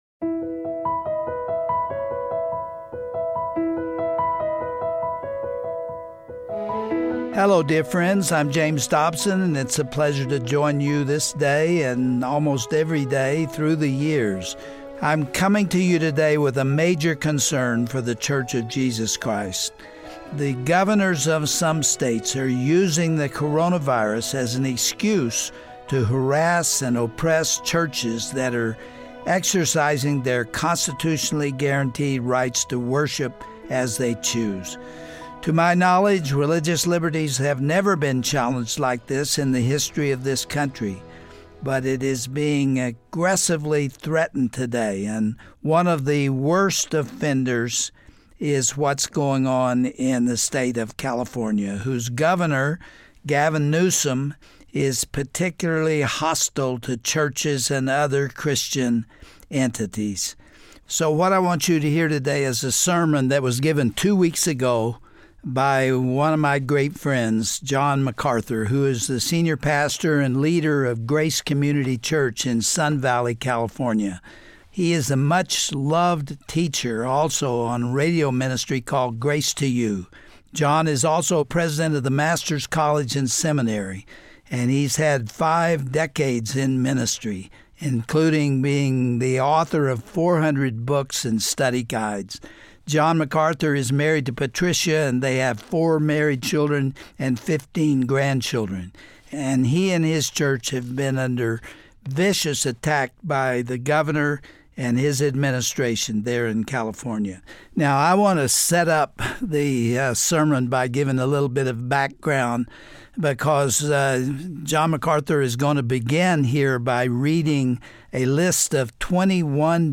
Host Dr. James Dobson
Guest(s):Pastor John MacArthur